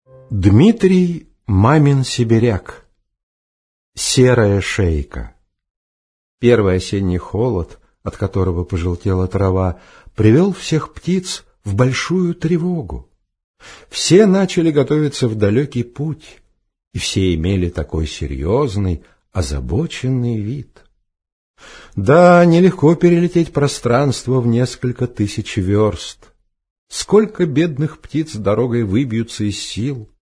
Аудиокнига Серая шейка | Библиотека аудиокниг